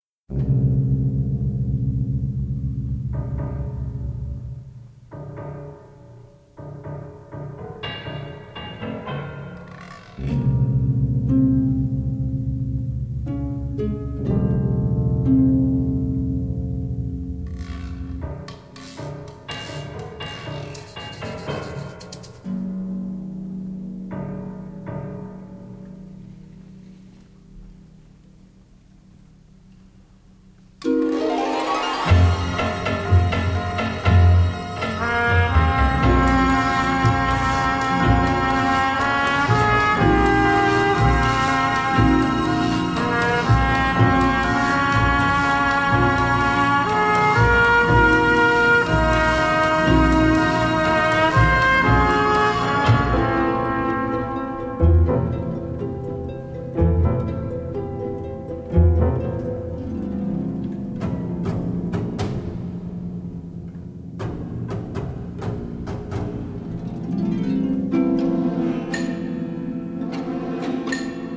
Original Motion Picture Soundtrack